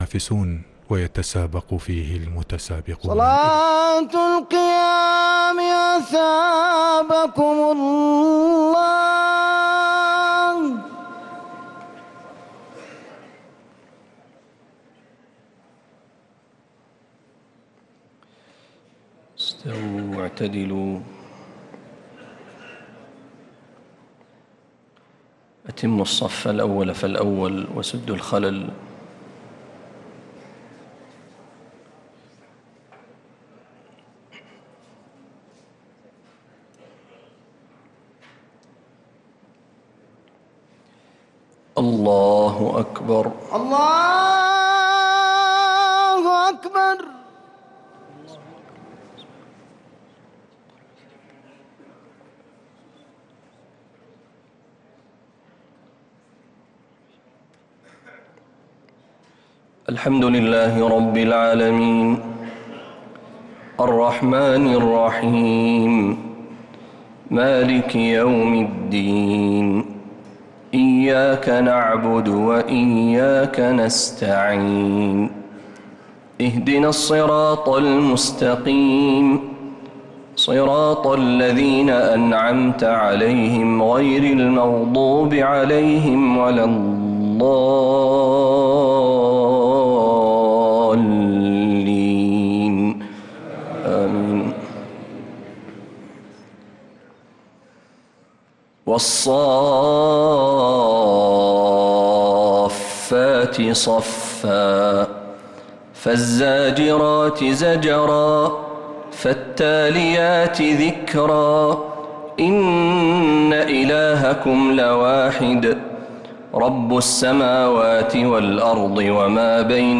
تراويح ليلة 25 رمضان 1446هـ من سورتي الصافات كاملة و ص (1-29) | taraweeh 25th night Ramadan 1446H Surah As-Saaffaat and Saad > تراويح الحرم النبوي عام 1446 🕌 > التراويح - تلاوات الحرمين